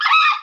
creek2.wav